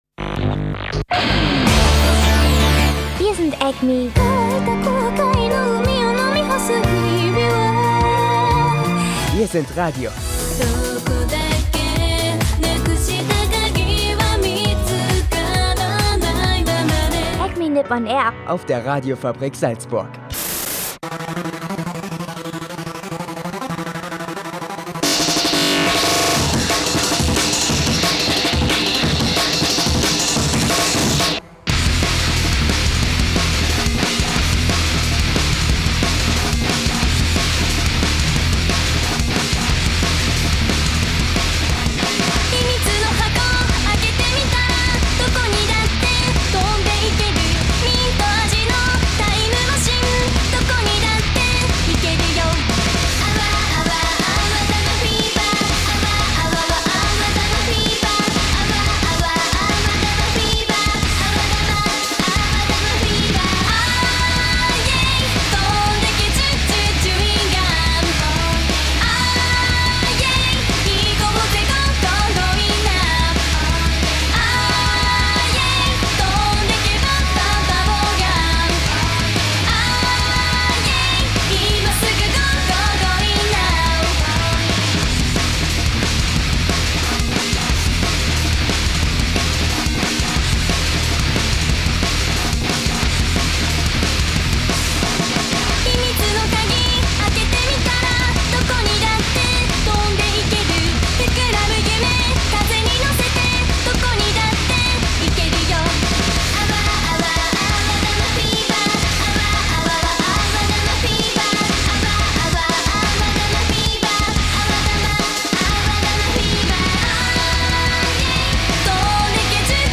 Diese Woche gibt es viel Musik und Infos für euch auf die Ohren. Dabei geht es unter anderem um Entwicklungen bei deutschen Anime-Conventions, um den japanischen Kaiser, es gibt einen frischen Animetipp zu einem süssen Anime, und auch das JapanWetter fehlt natürlich nicht.